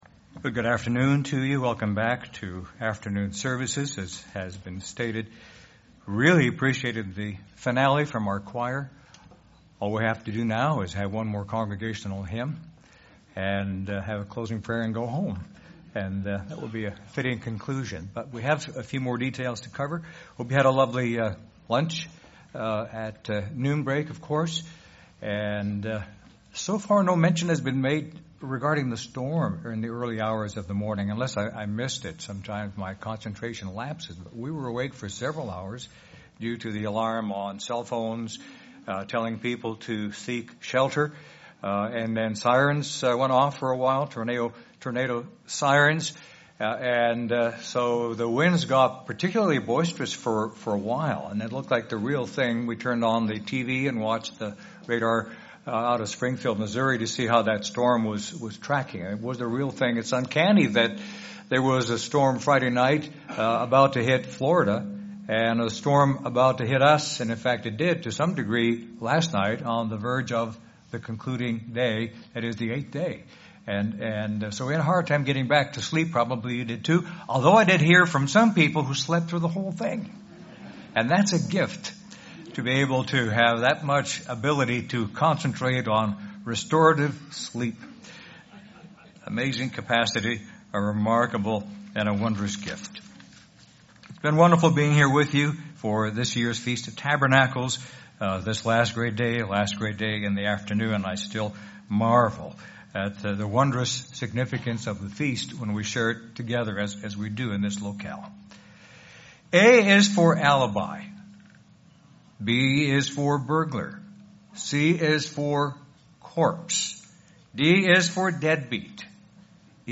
This sermon was given at the Branson, Missouri 2019 Feast site.